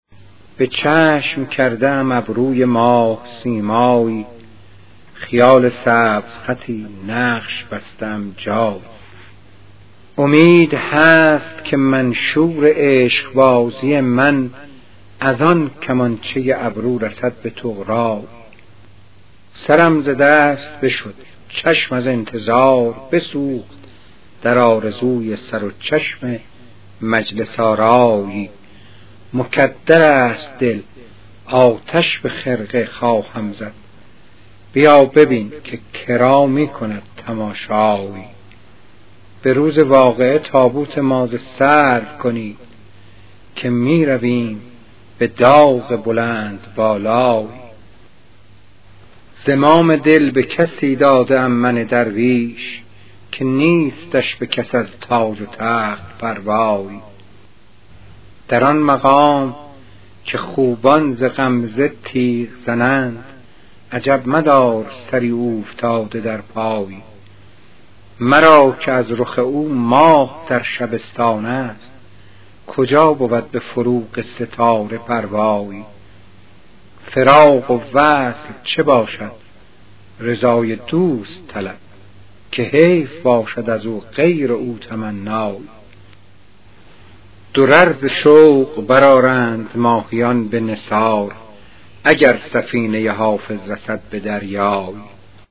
پخش صوتی غزل